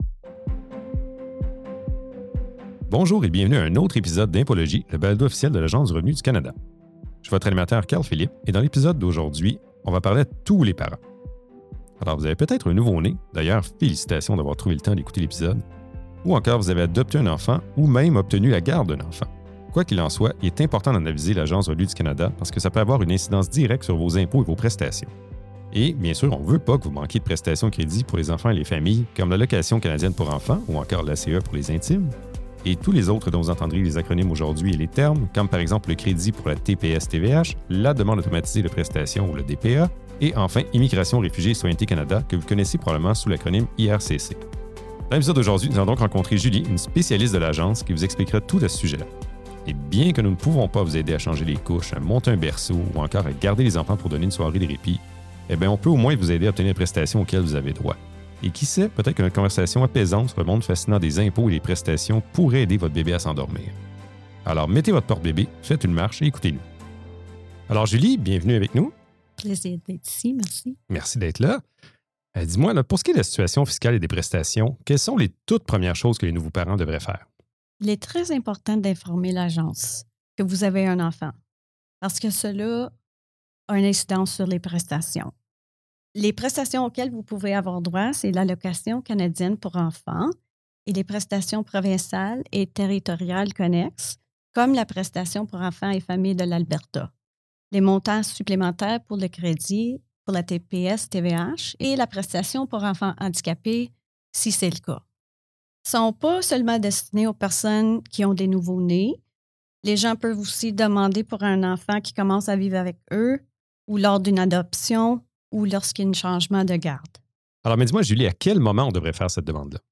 Dans cet épisode d’Impôlogie, une experte de l’ARC vous explique ce qu’est l’ACE, démystifie certaines idées reçues et vous montre comment en faire la demande.